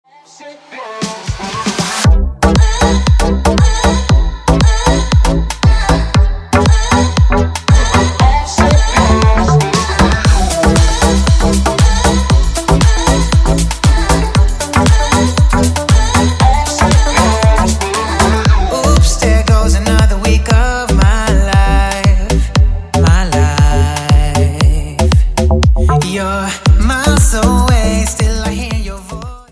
• Качество: 128, Stereo
поп
мужской вокал
dance
Electronic